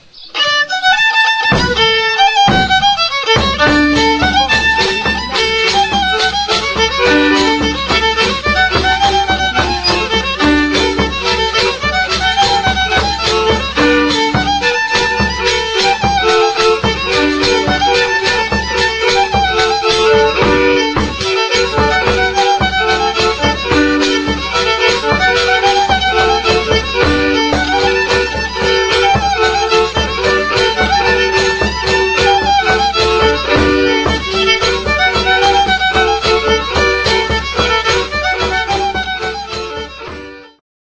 1 skrzypce
nagr. Gródki, 2004
bębenek